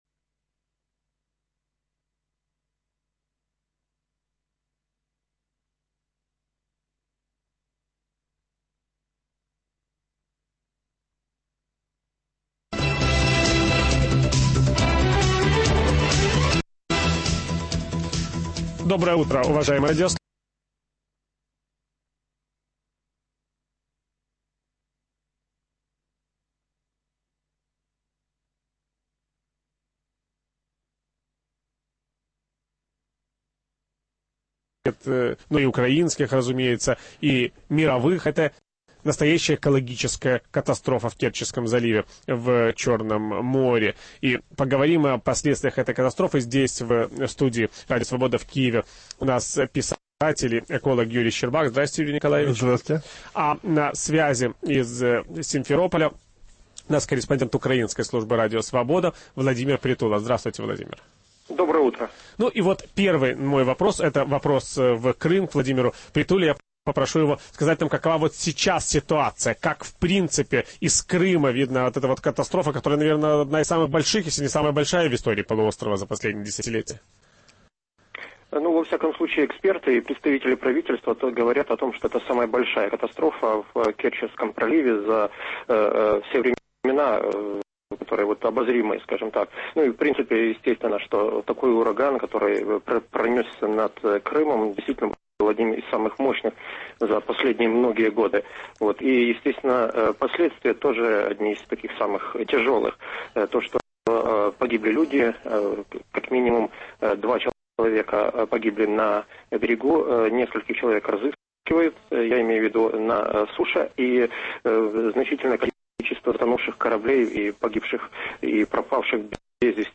ведущий программы Виталий Портников говорит с писателем Юрием Щербаком